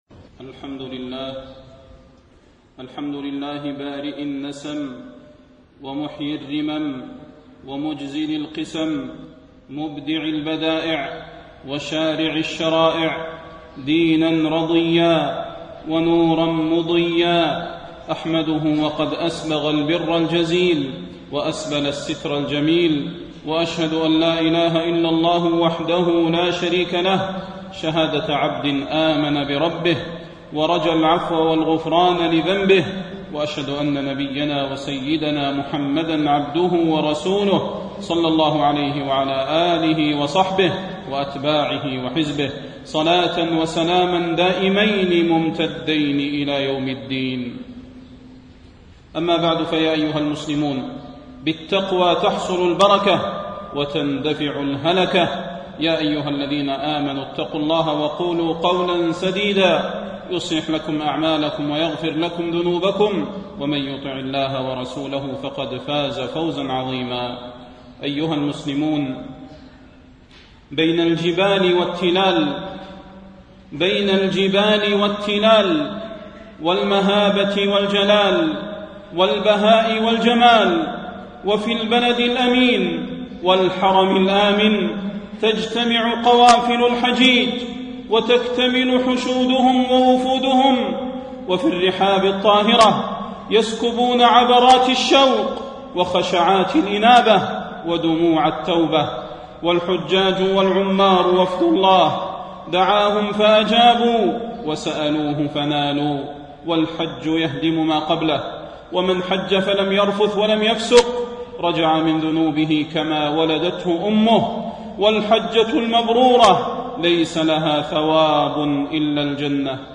خطب الحرم المكي